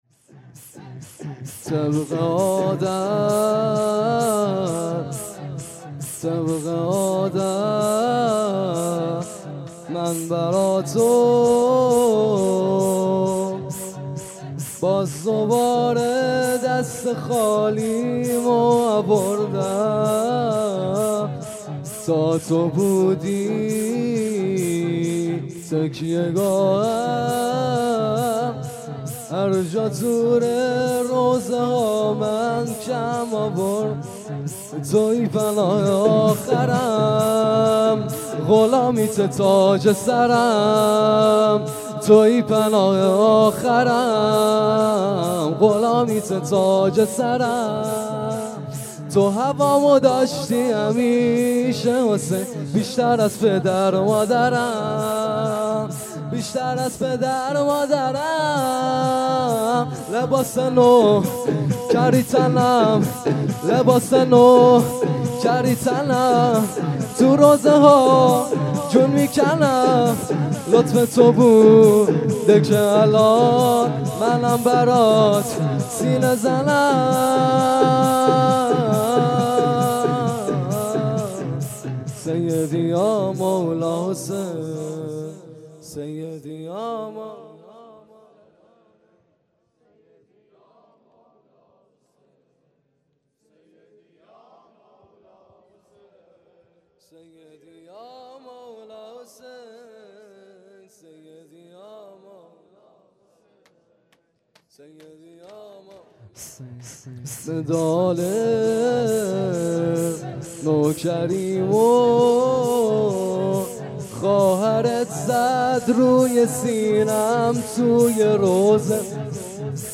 شور | دست خالیمو آوردم
گزارش صوتی شب پنجم محرم 96 | هیأت محبان حضرت زهرا سلام الله علیها زاهدان